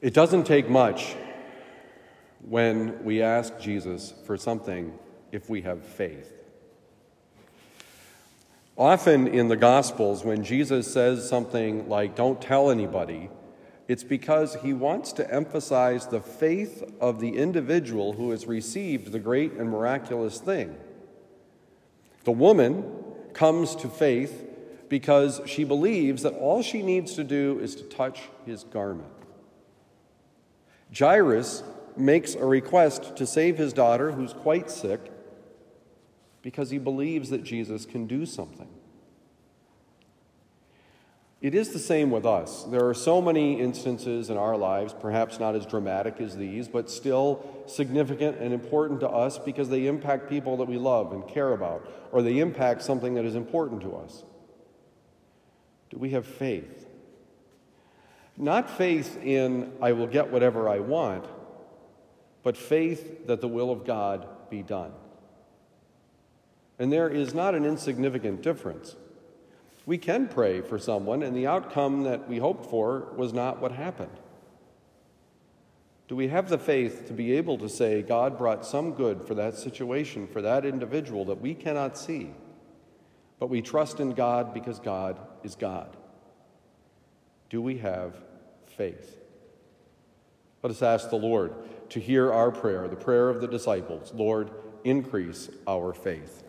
Do you have faith: Homily for Tuesday, January 31, 2023
Given at Christian Brothers College High School, Town and Country, Missouri.